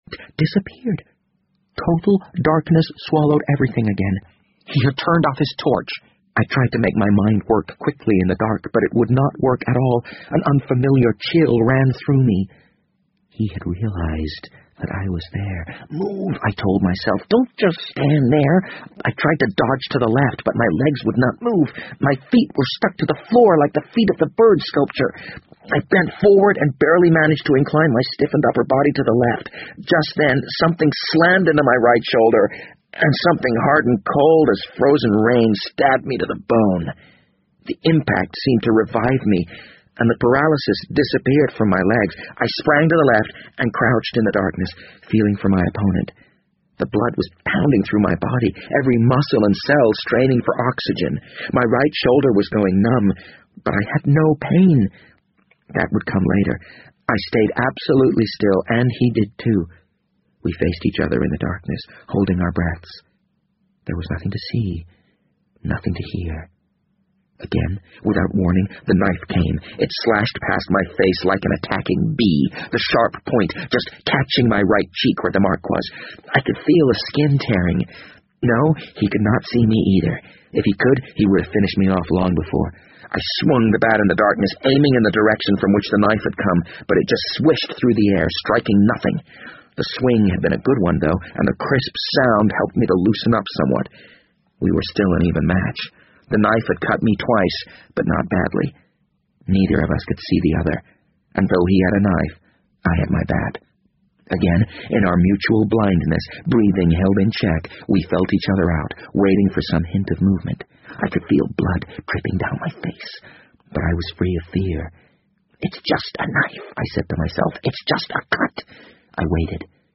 BBC英文广播剧在线听 The Wind Up Bird 015 - 10 听力文件下载—在线英语听力室